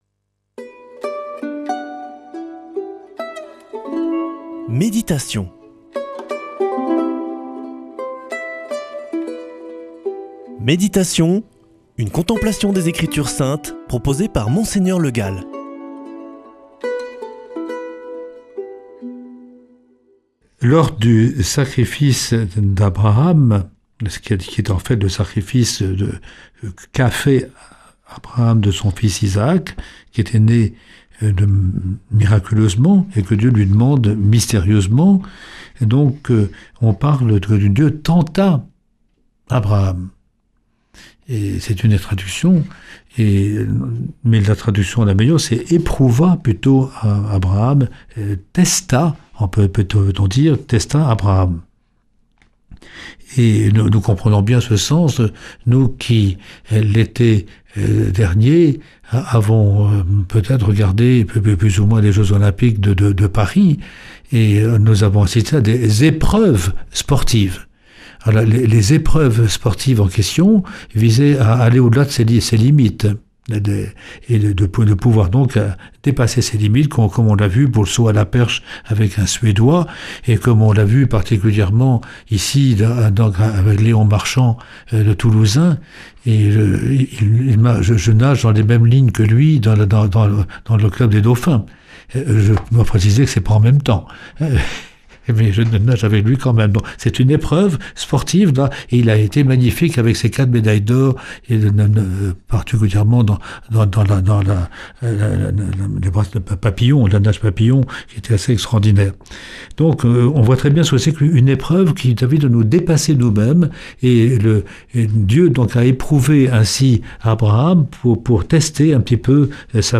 Méditation avec Mgr Le Gall